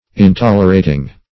Intolerating \In*tol"er*a`ting\, a.